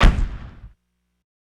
SouthSide Kick Edited (23).wav